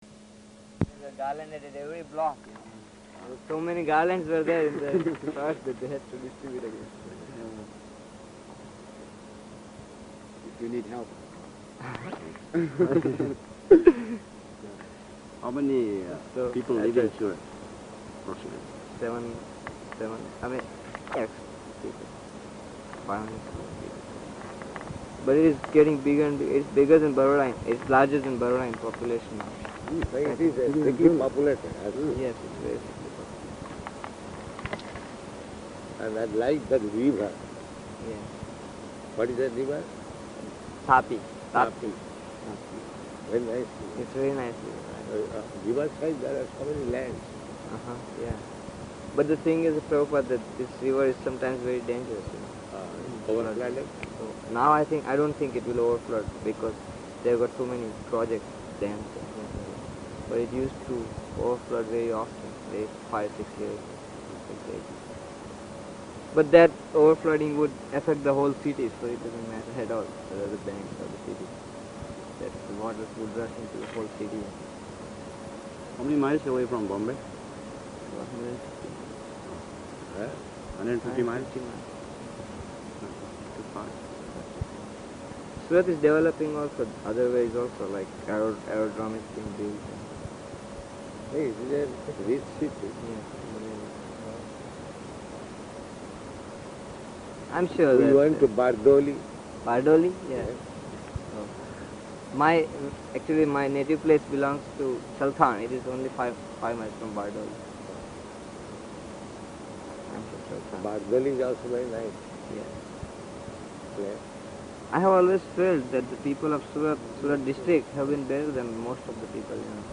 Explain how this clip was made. Location: Pittsburgh